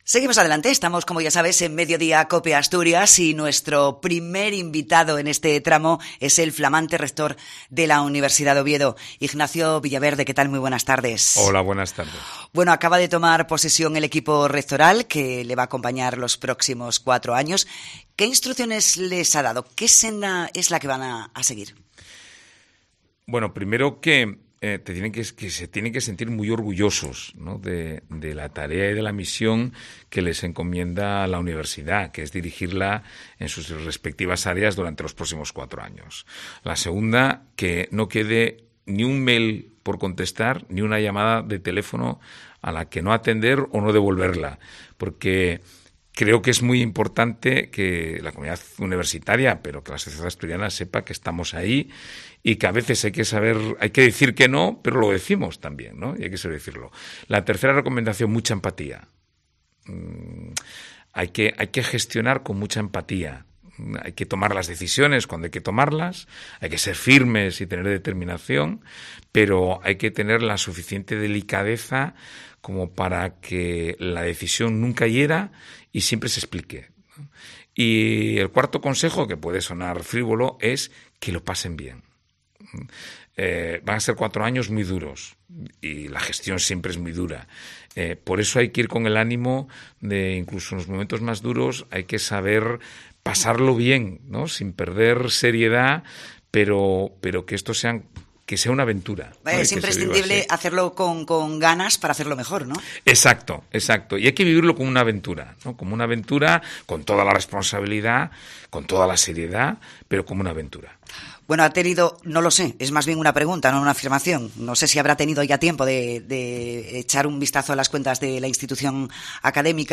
El rector de la Universidad de Oviedo, Ignacio Villaverde, visita Cope Asturias